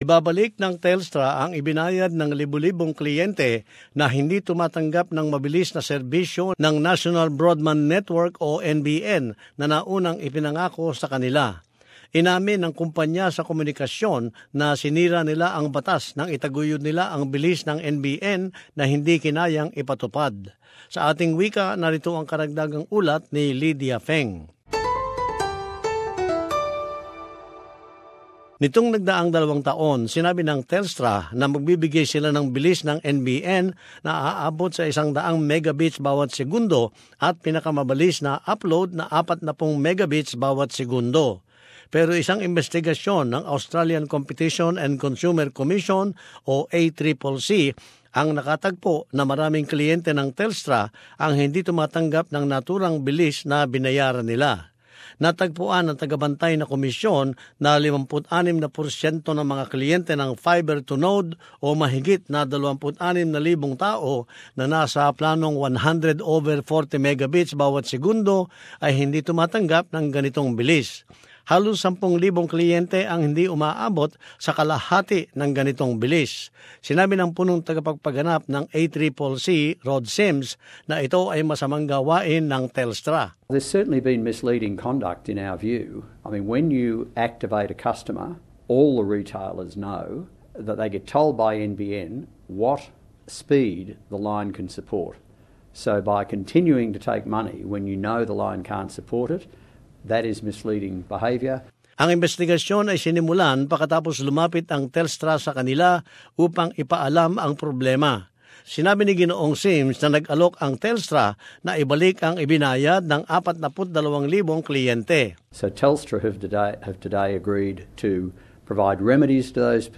karagdagang ulat